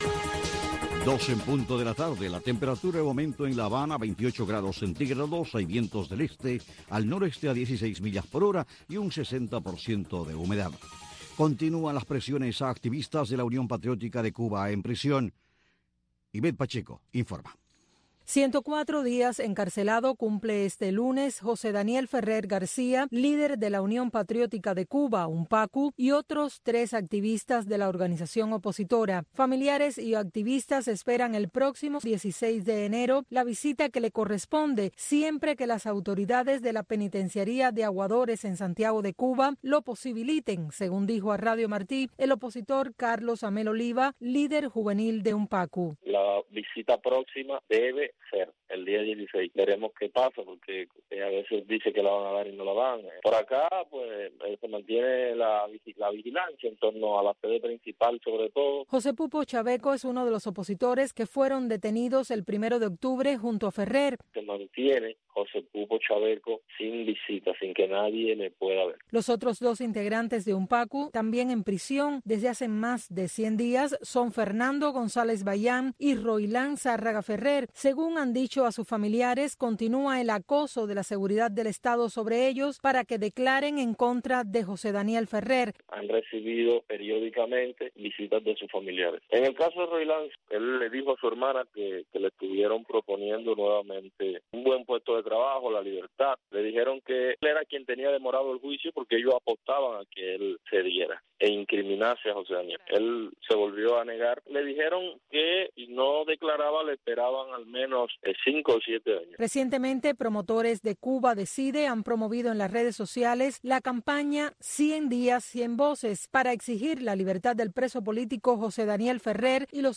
Un espacio informativo con énfasis noticioso en vivo donde se intenta ofrecer un variado flujo de información sobre Cuba, tanto desde la isla, así como desde el exterior.